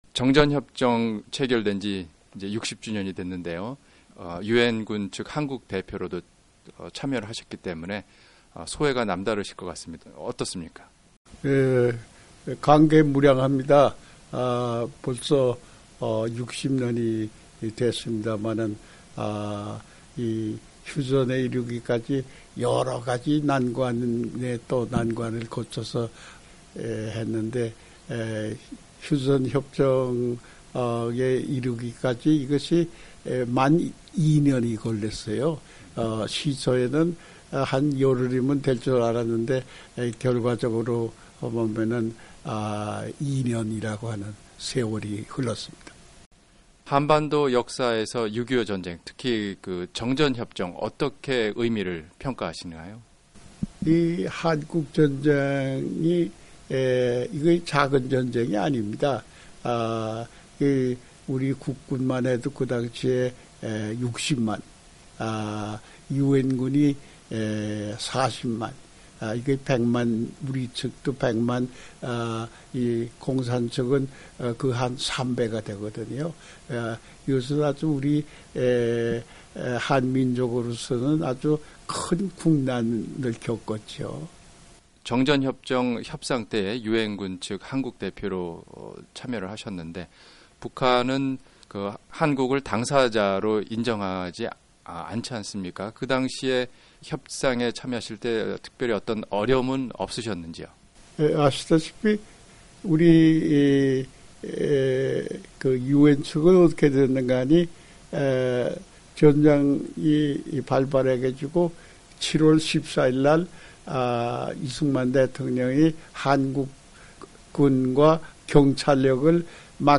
백선엽 장군이 한국전 정전협정 60주년을 맞아 한국의 대통령 특사단의 일원으로 워싱턴을 방문했는데요, VOA가 인터뷰했습니다.